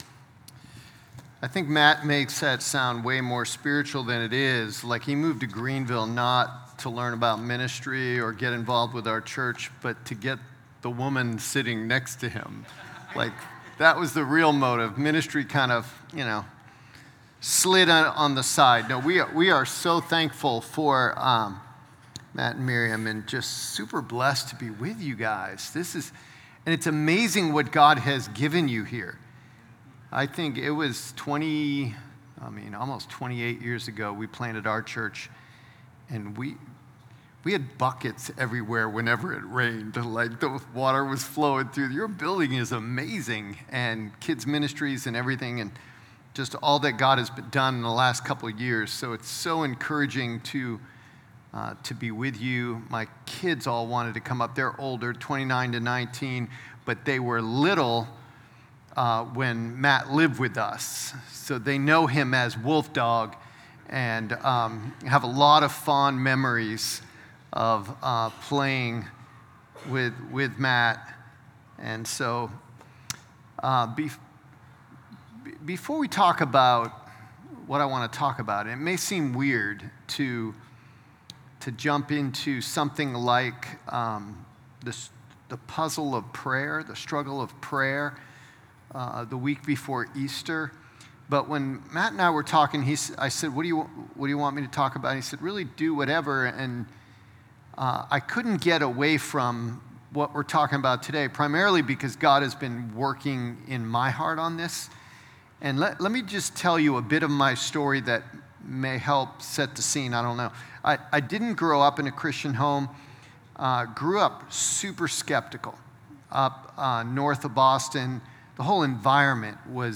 Sermon0414_Pray-When-Nothing-Happens.m4a